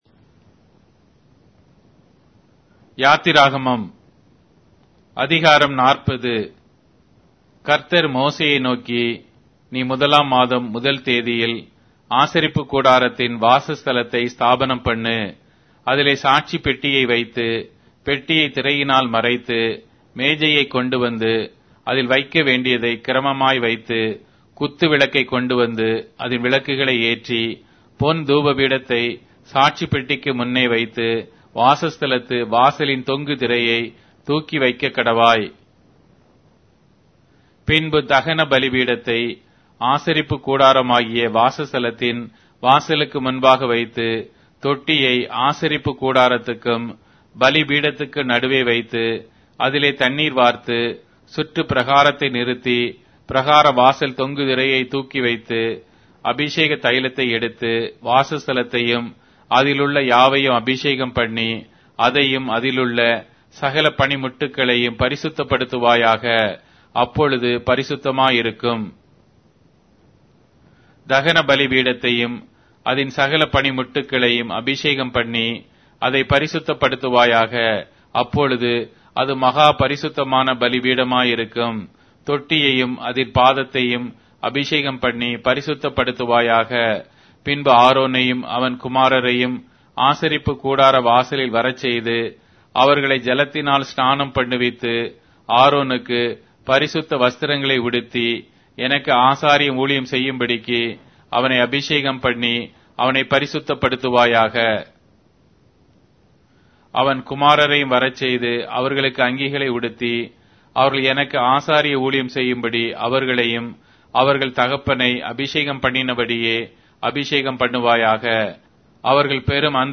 Tamil Audio Bible - Exodus 17 in Asv bible version